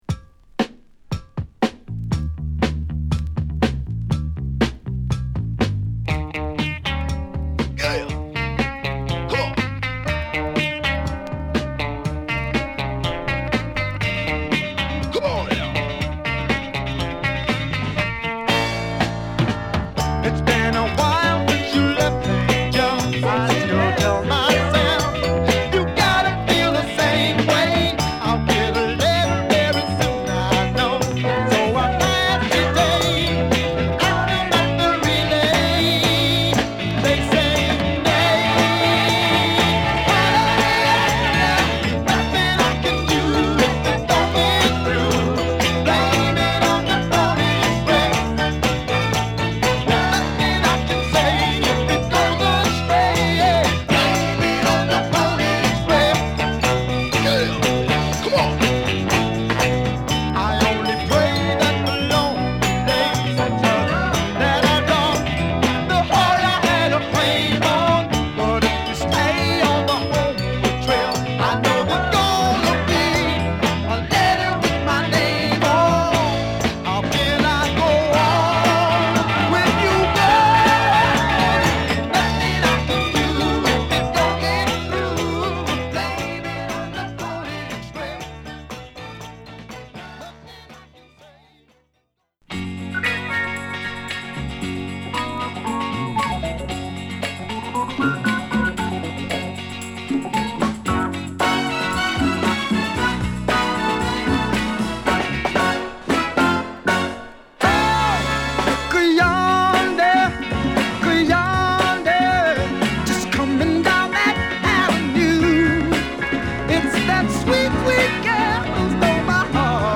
固めのドラム＆ベースのブレイクから始まり、晴れやかな歌メロのモッズ／ノーザンソウル人気曲！